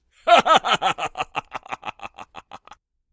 male_laugh1.ogg